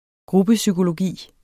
Udtale [ ˈgʁubəsygoloˌgiˀ ]